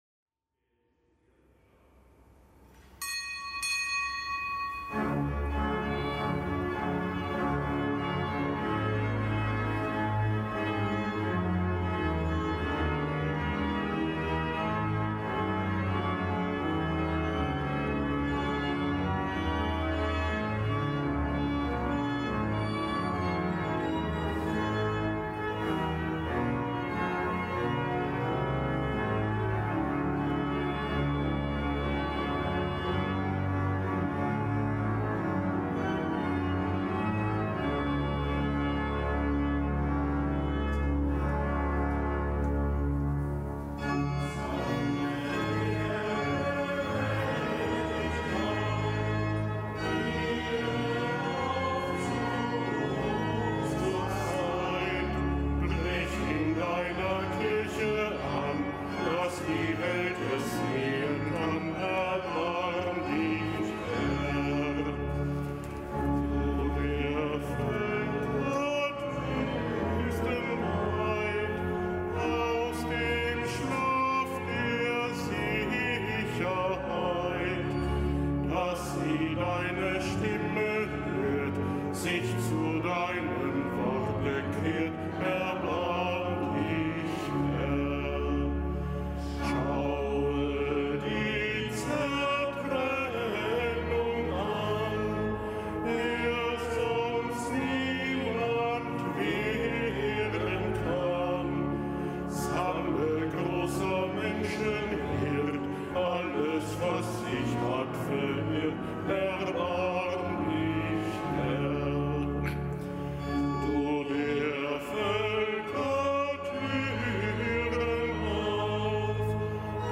Kapitelsmesse aus dem Kölner Dom am Fest des heiligen Cyrill und des heiligen Methodius
Kapitelsmesse aus dem Kölner Dom am Fest des heiligen Cyrill, Mönch und des heiligen Methodius, Bischof, Glaubensboten bei den Slawen, Schutzpatrone Europas.